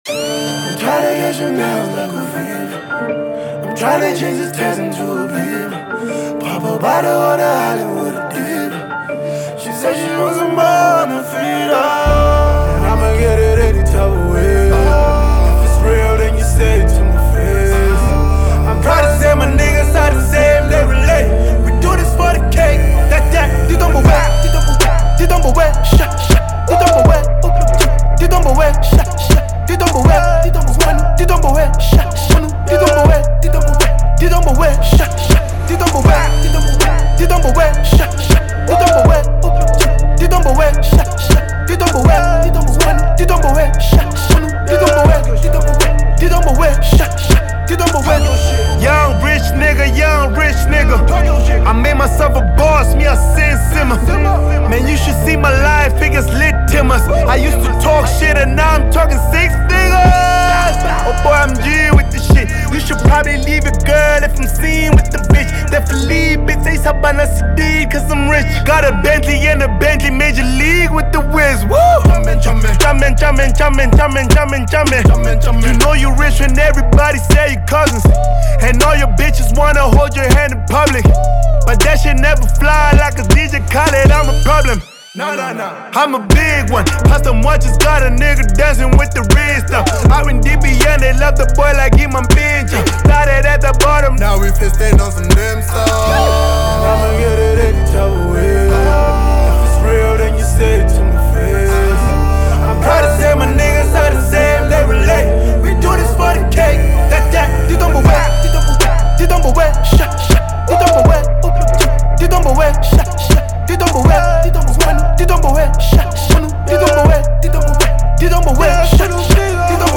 South African rap phenomenon and continental superstar